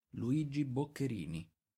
Ridolfo Luigi Boccherini[1] (/ˌbɒkəˈrni/,[2][3] also US: /ˌbk-/,[4][5] Italian: [riˈdɔlfo luˈiːdʒi bokkeˈriːni]
It-Luigi_Boccherini.ogg.mp3